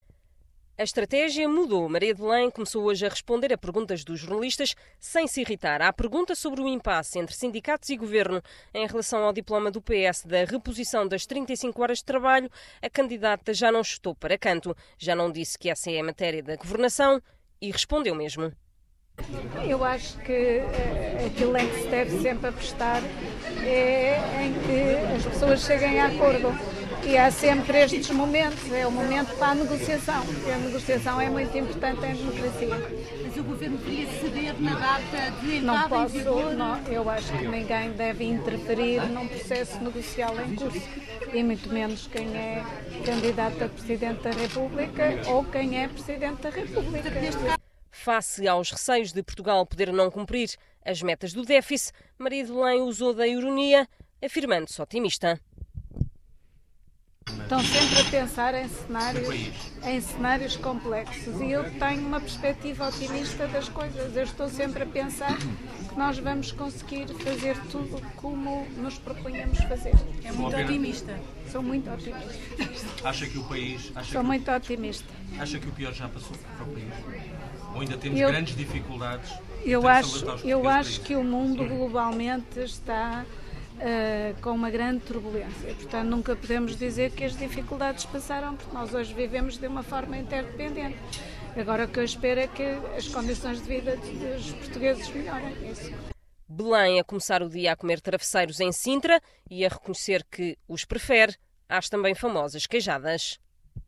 Maria de Belém na campanha em Sintra.